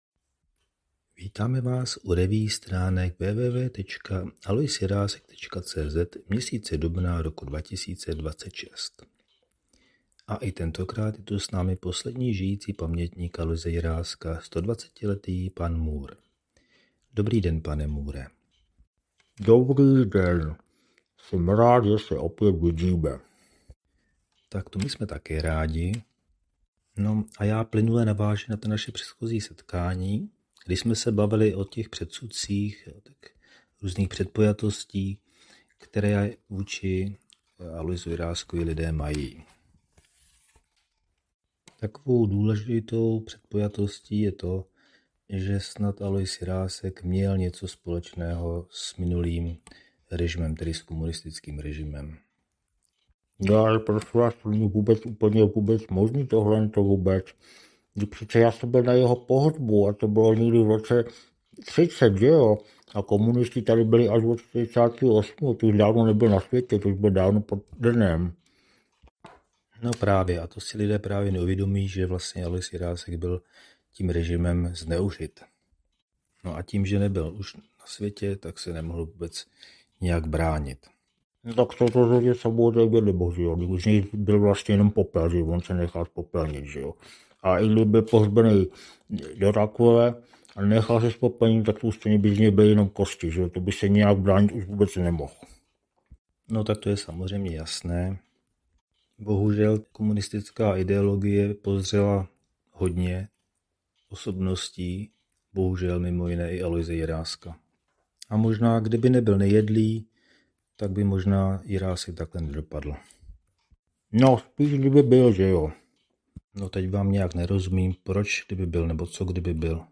Další pokus o zvukovou revue si s obvyklou humornou nadsázkou bere na mušku jeden z nejčastějších a nejzakořeněnějších předsudků o Aloisu Jiráskovi – a sice naprosto mylné přesvědčení o tom, že měl cosi společného s komunistickým režimem, který zde vládl od roku 1948, ač Jirásek sám komunistou nikdy nebyl a zemřel již v roce 1930.